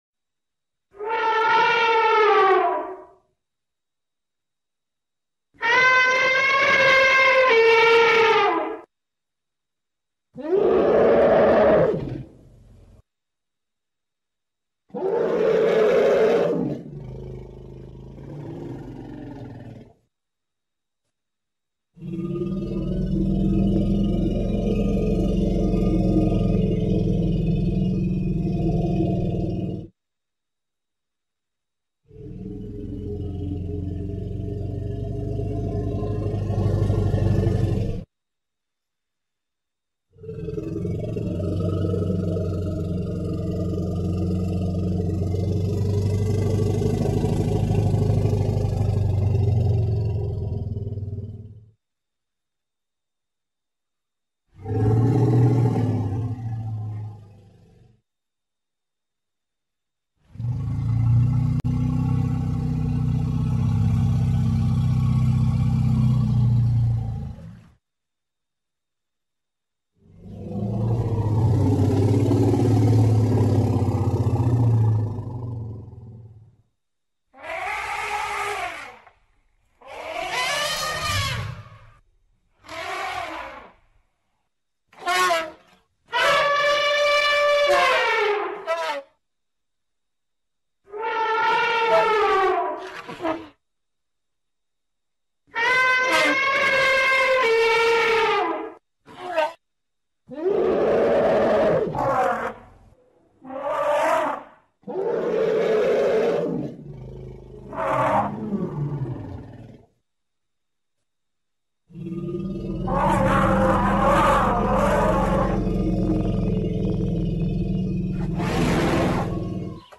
Tiếng Voi kêu
Download tiếng voi kêu mp3 chất lượng cao, tải hiệu ứng âm thanh tiếng voi kêu mp3, tải tiếng voi kêu chất lượng cao, tải tiếng gầm của voi, tiếng voi gọi bầy...